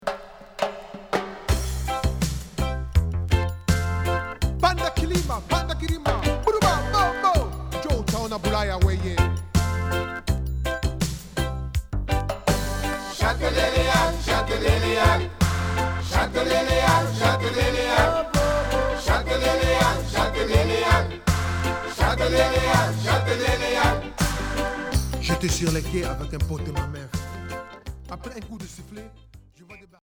dub version
Reggae